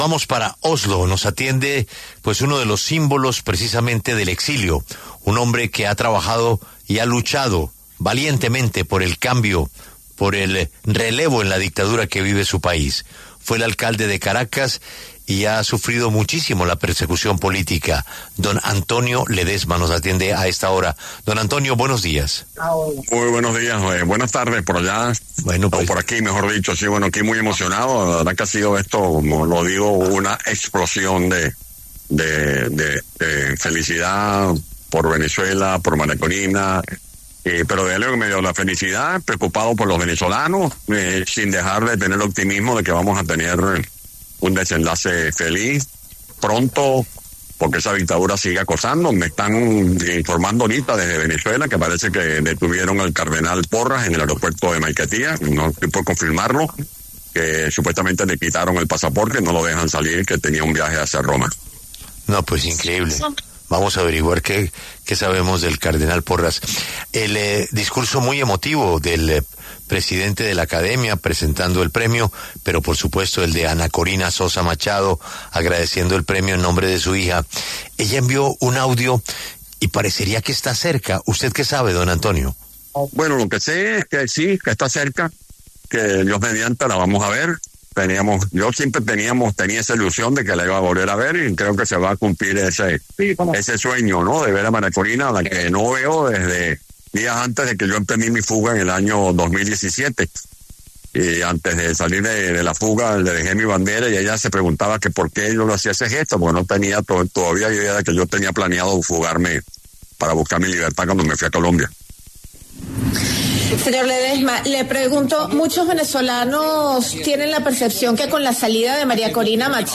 Antonio Ledezma, exalcalde de Caracas, Venezuela, habló en La W a propósito del Nobel de Paz otorgada a la líder opositora venezolana Maria Corina Machado.